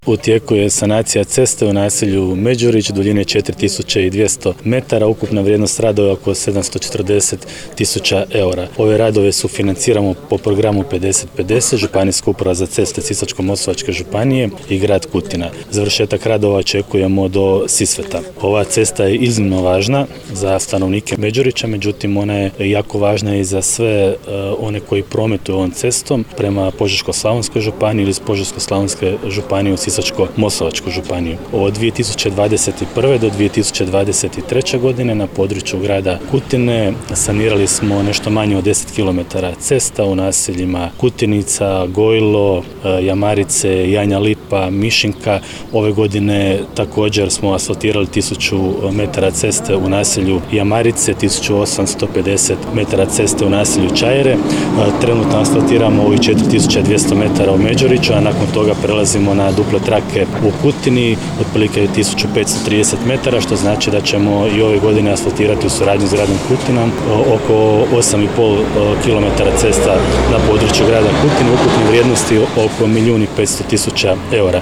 Završetak ovih radova u petak, 25. listopada 2024. godine, obišao je župan Ivan Celjak sa suradnicima